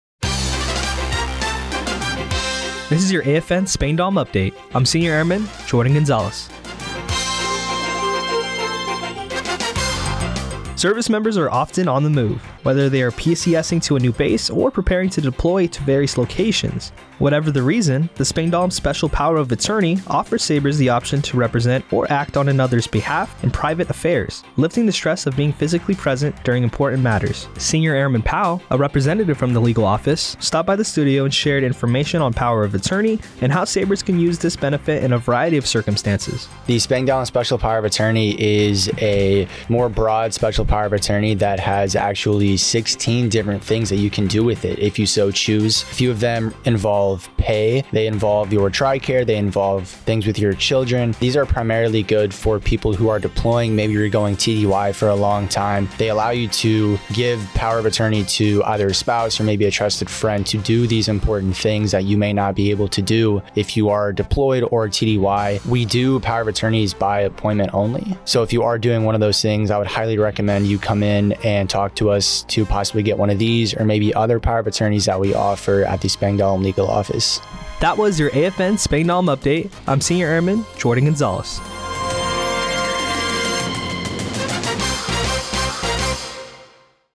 Radio news on 10-16-24 on Power of Attorney.